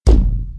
hit_spiderweb.ogg